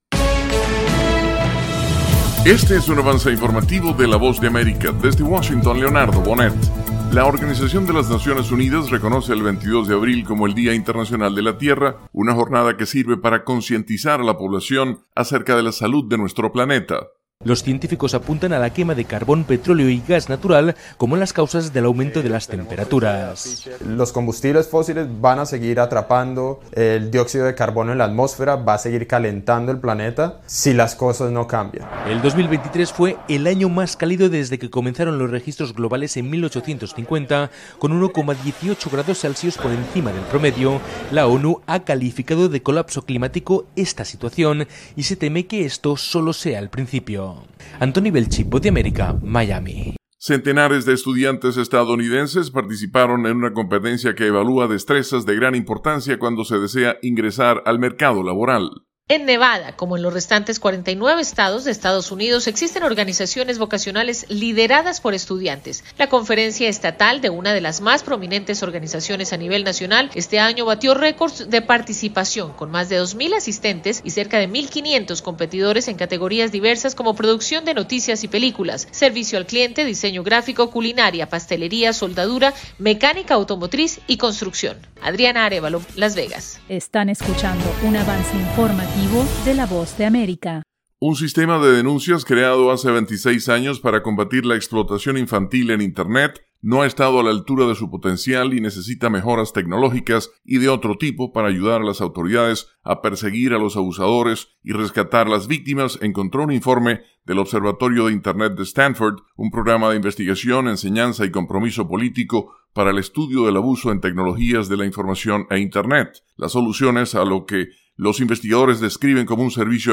Avance Informativo